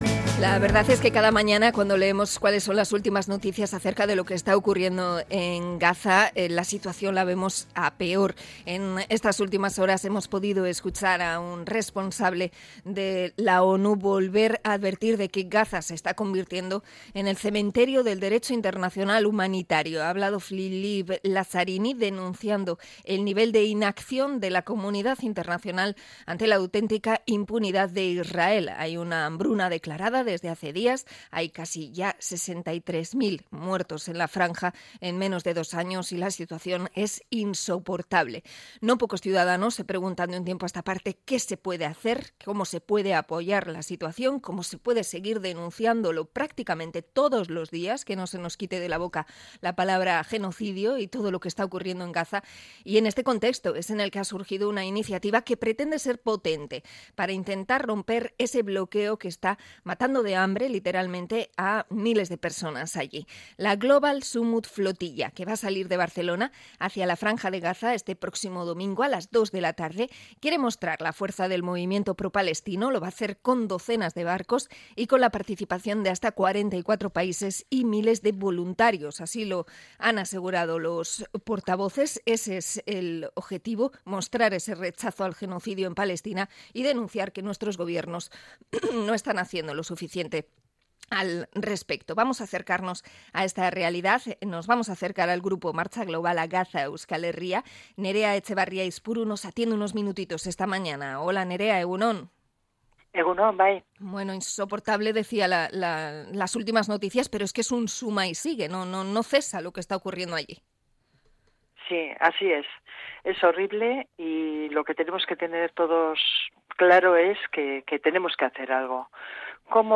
Hablamos con los participantes vascos en la Global Sumud Flotilla